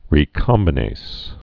(rē-kŏmbə-nās, -nāz)